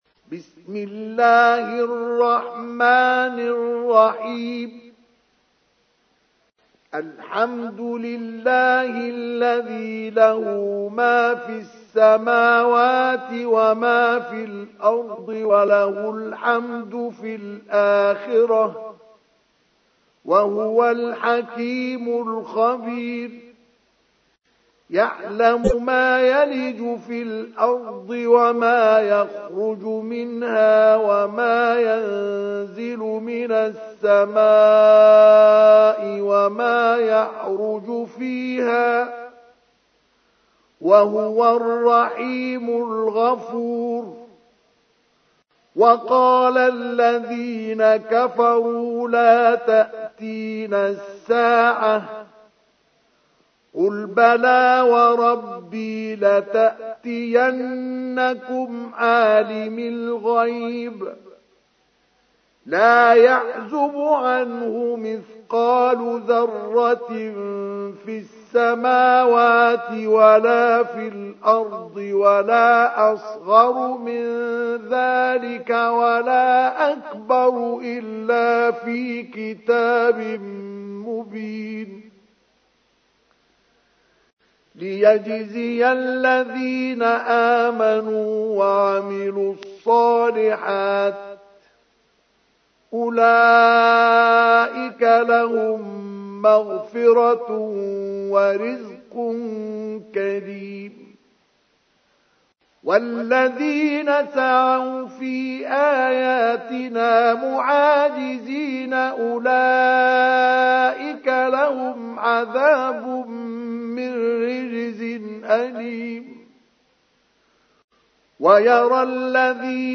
تحميل : 34. سورة سبأ / القارئ مصطفى اسماعيل / القرآن الكريم / موقع يا حسين